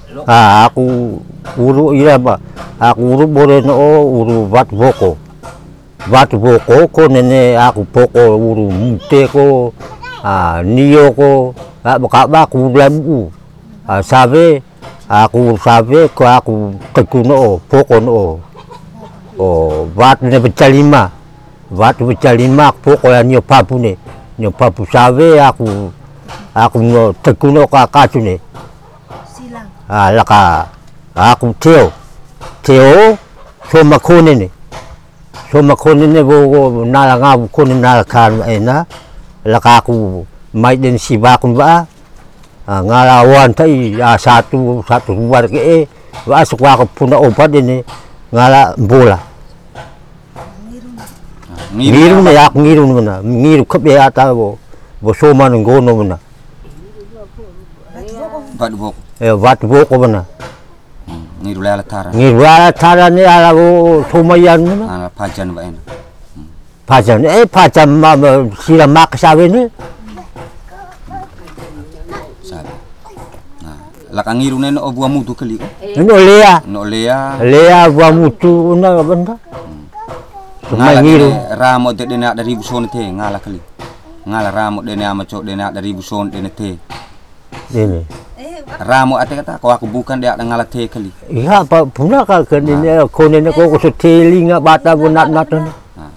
Genre: Medicine/huru.
It gets loud easily, so turn down the volume.